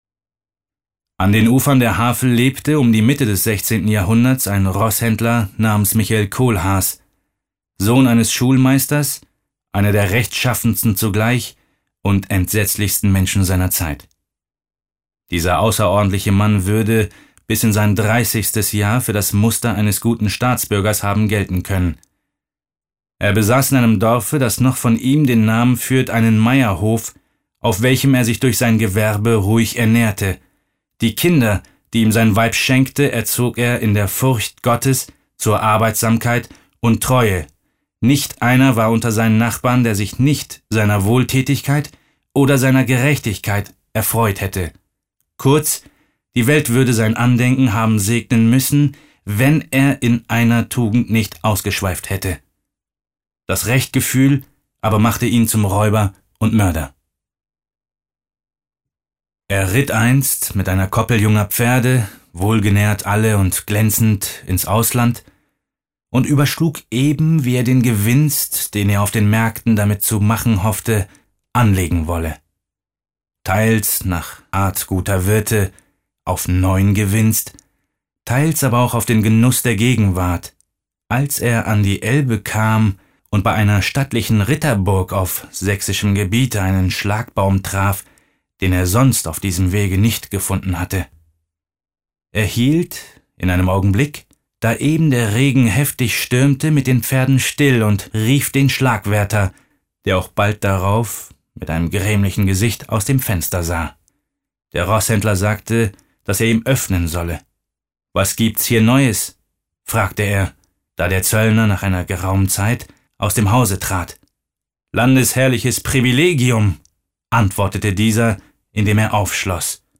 Mehmet Kurtulus (Sprecher)
Ungekürzt.